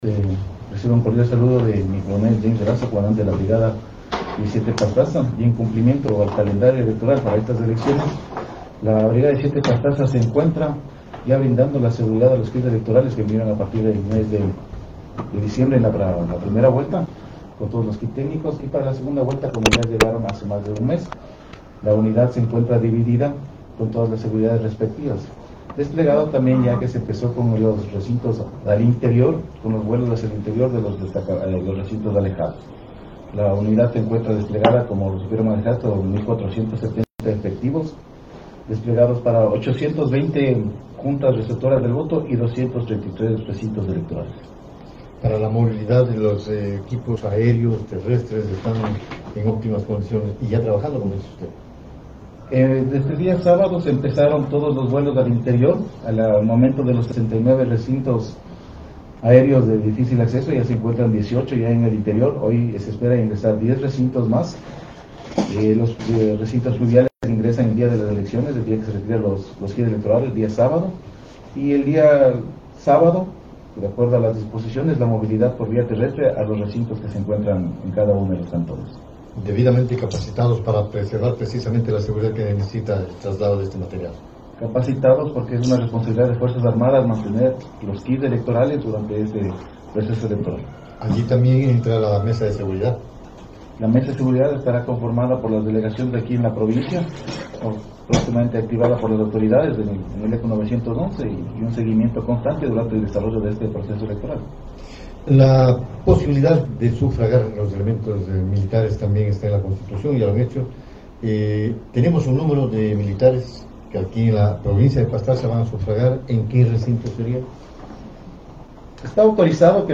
En una entrevista en Nina Radio en Puyo el 08 de abril de 2025